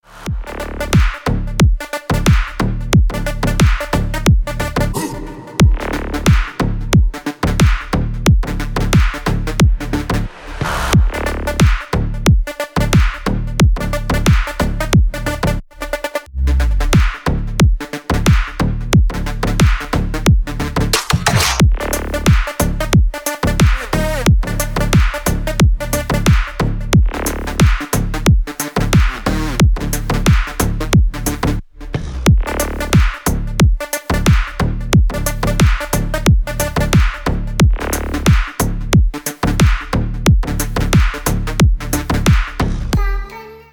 Четкие рингтоны из клубного жанра
• Песня: Рингтон, нарезка